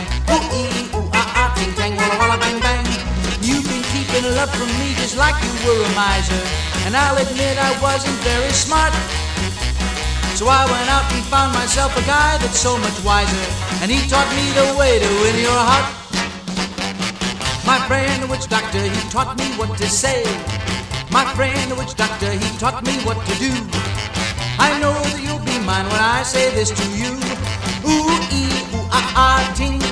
Жанр: Поп музыка
Pop, Vocal, Comedy, Novelty